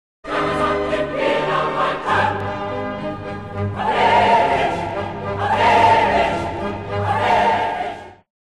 Konzertwalzer
für gemischten Chor und Orchester
Besetzung: gemischter Chor, Klavier (ggf. 4-händig)